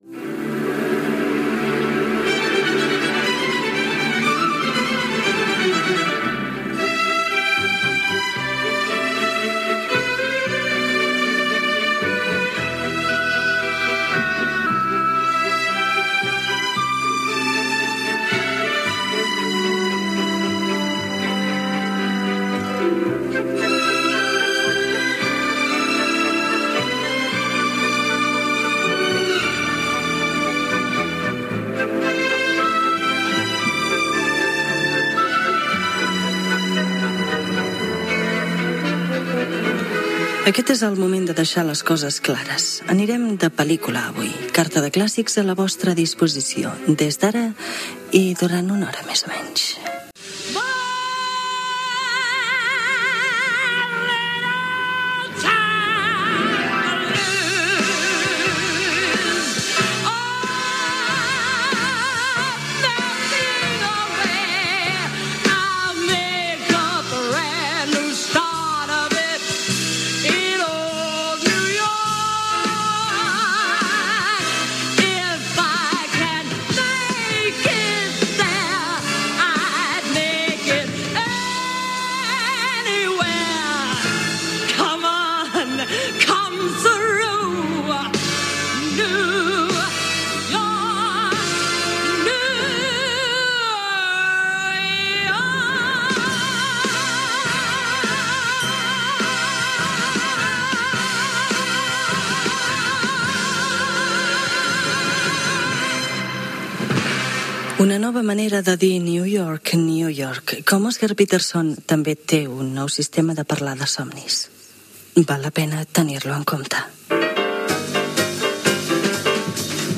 Sintonia, presentació, espai dedicat a la música de pel·lícules i dos temes musicals
Musical